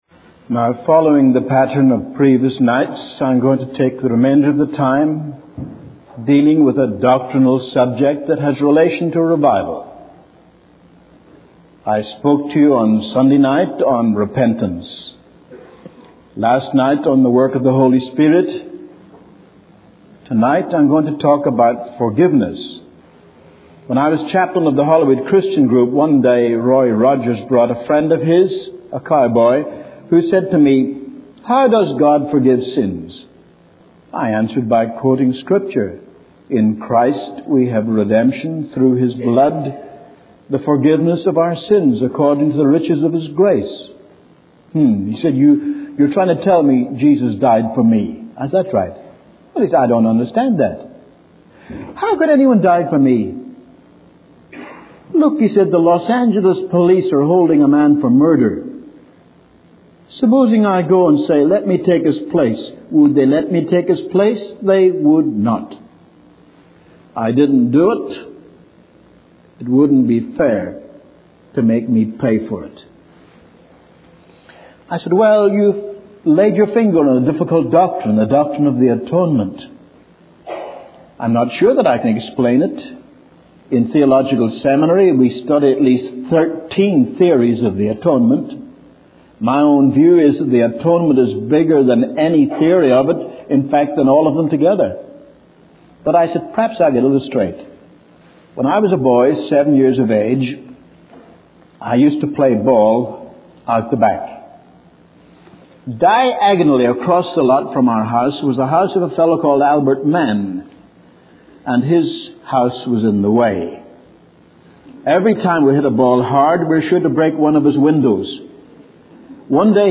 In this sermon, the speaker shares about a series of meetings where he witnessed the power of prayer and confession. He emphasizes the importance of being specific in our prayers and seeking God's guidance to identify our sins.